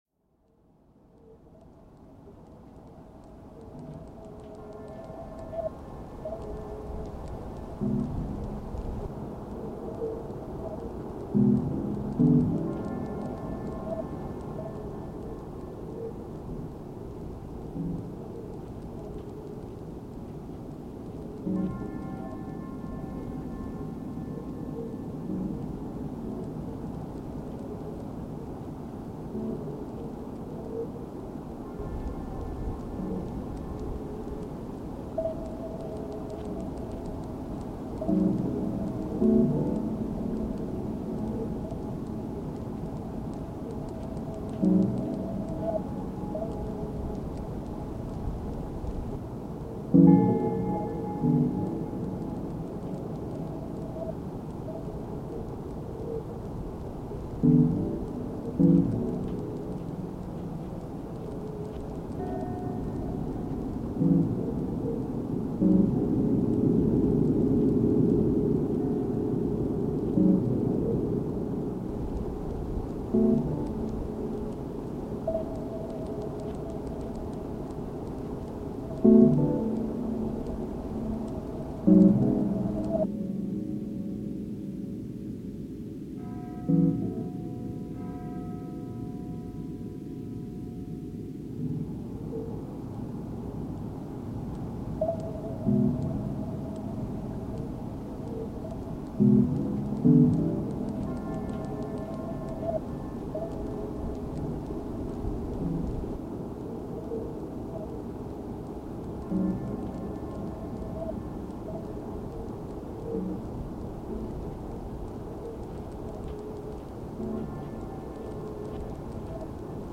Soundfields and Compositions
Thinking edit, to be played at low volume; in the background